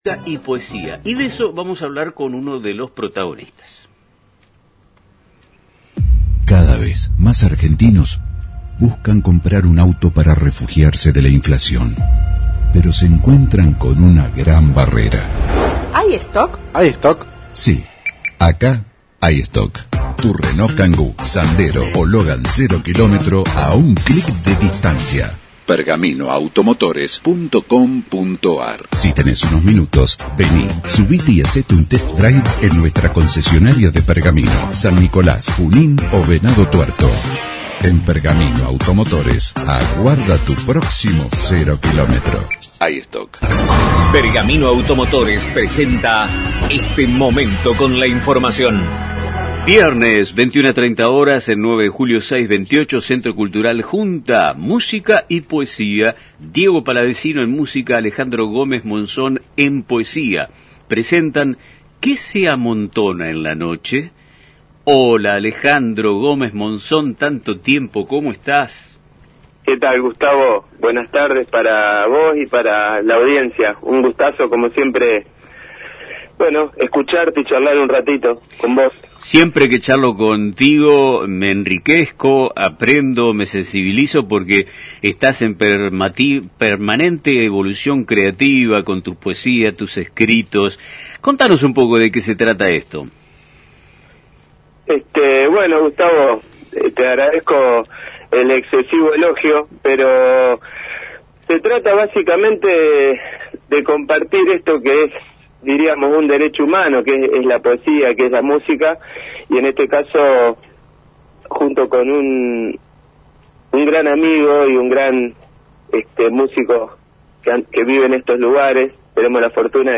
En una reciente entrevista en el programa «Nuestro Tiempo» de Radio Mon Pergamino